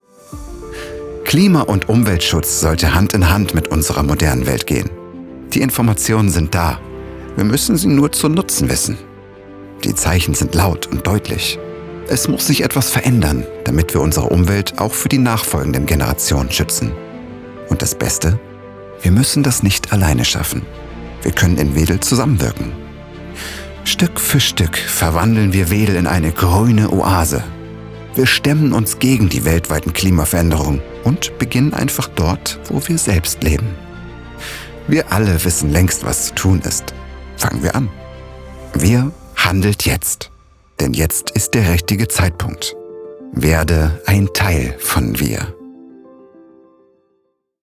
dunkel, sonor, souverän, plakativ
Mittel plus (35-65)
Imagefilm 01 - natürlich und sanft